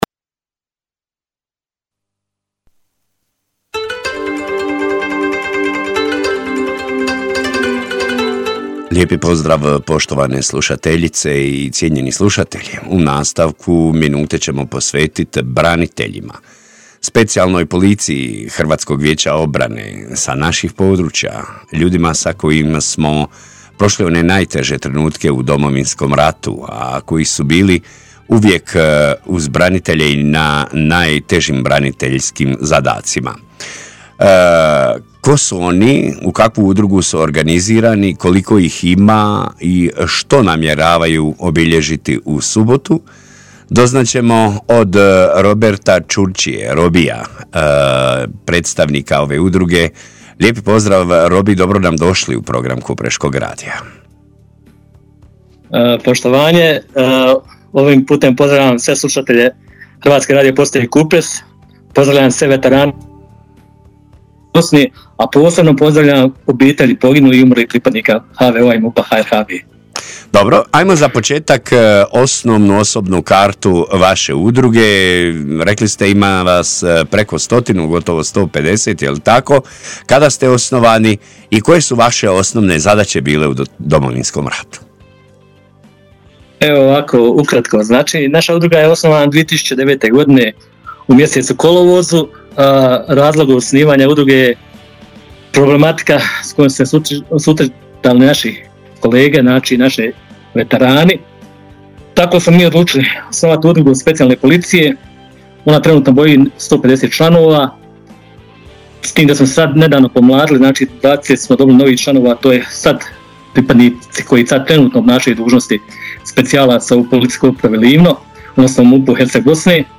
Razgovor poslušajte ovdje: udruga_specijalne_policije_domov.mp3